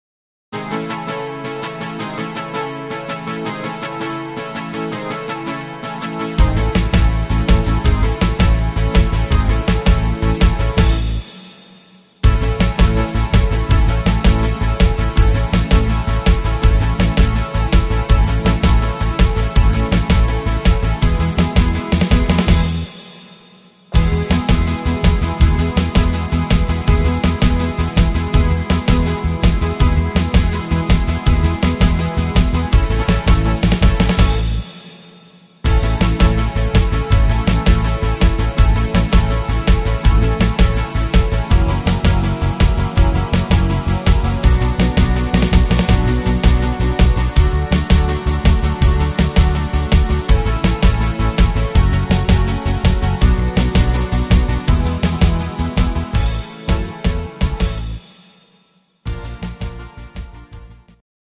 instr. Gitarre